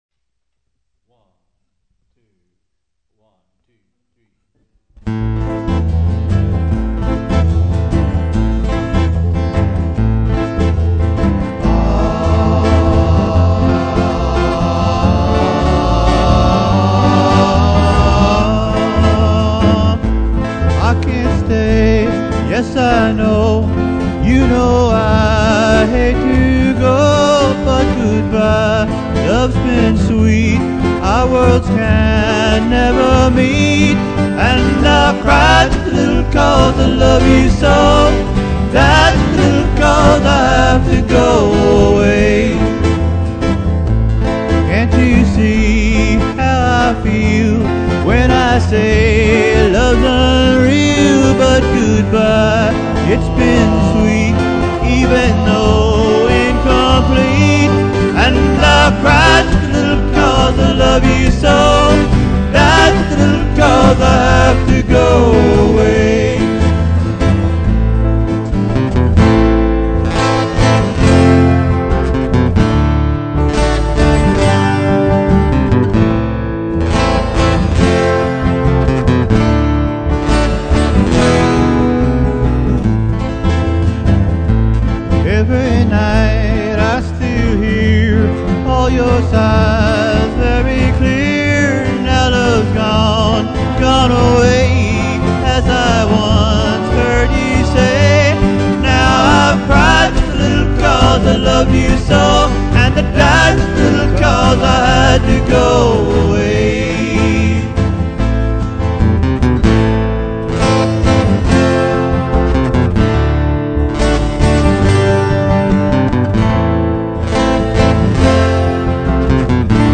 Все инструменты и вокал - это я.